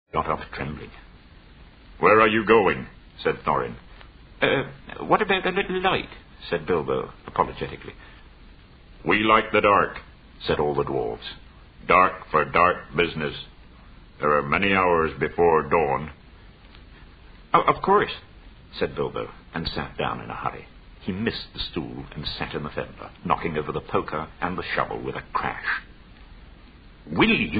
К сожалению, начитанная версия произведения и она немного не совпадает с рассылкой, но, в принципе, разобраться можно .